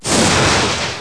lightnin.wav